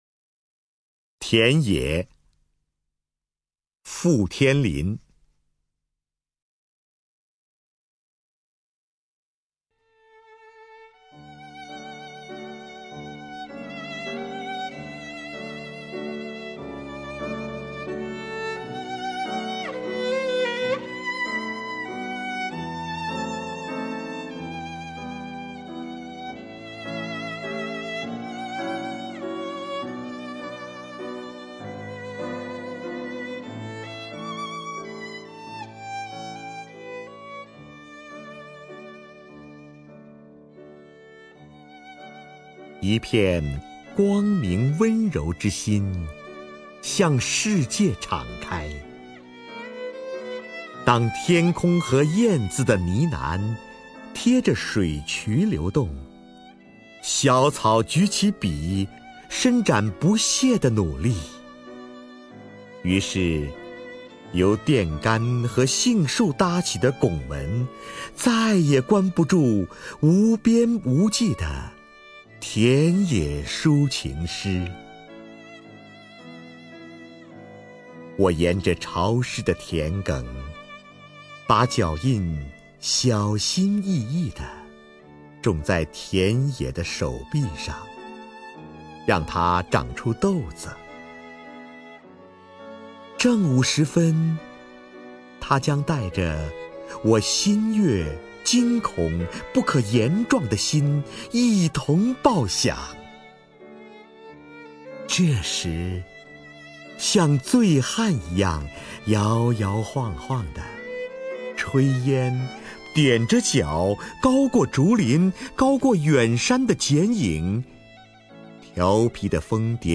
瞿弦和朗诵：《田野》(傅天琳)
名家朗诵欣赏 瞿弦和 目录
TianYe_FuTianLin(QuXianHe).mp3